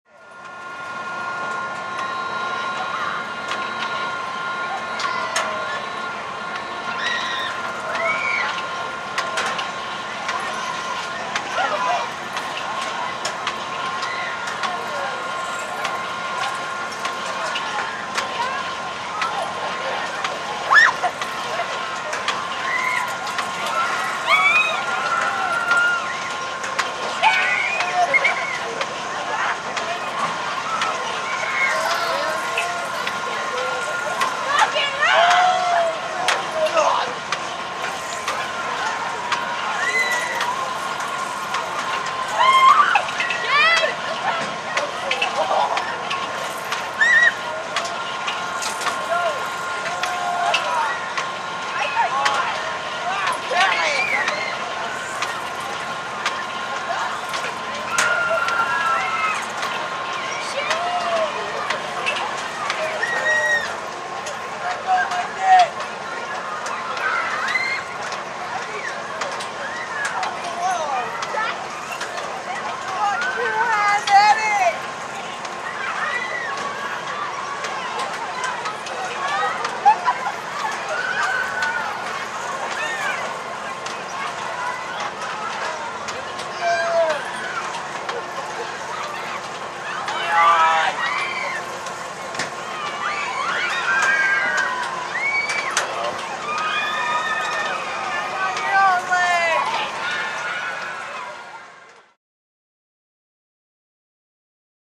Ride; Carnival Ride Operates With Metallic Clanks, Whir, And Passenger Screams.